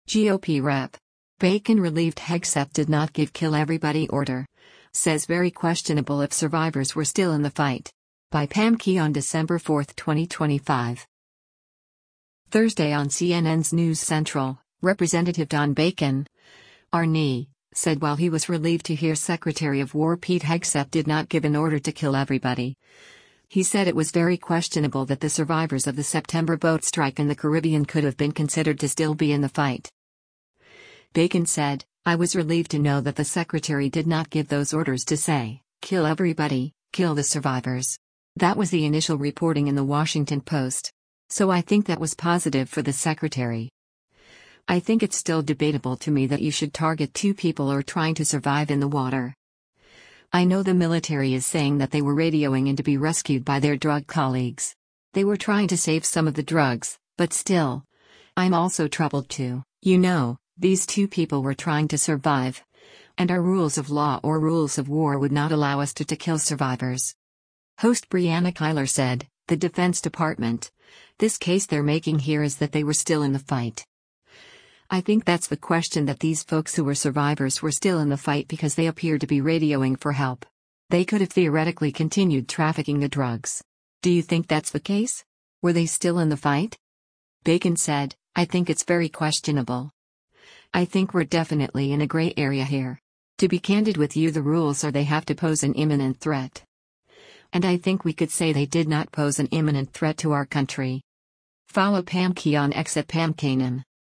Thursday on CNN’s “News Central,” Rep. Don Bacon (R-NE) said while he was relieved to hear Secretary of War Pete Hegseth did not give an order to “kill everybody,” he said it was “very questionable” that the survivors of the September boat strike in the Caribbean could have been considered to still be in the fight.